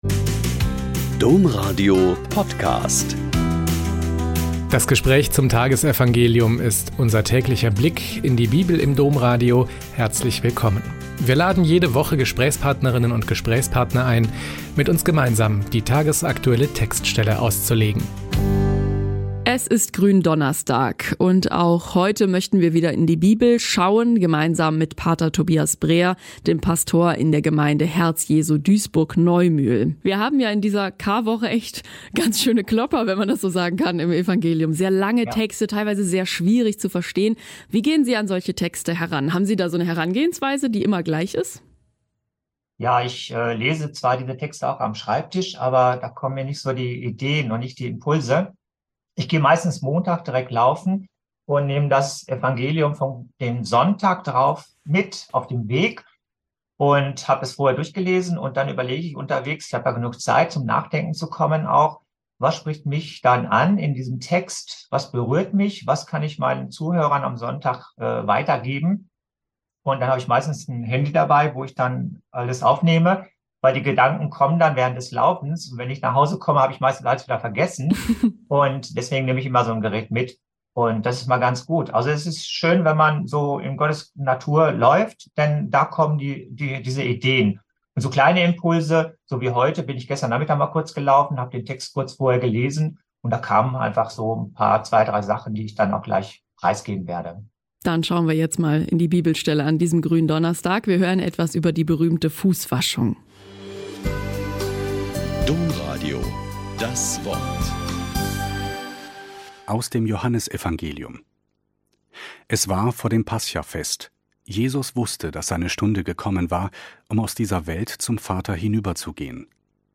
Joh 13,1-15 - Gespräch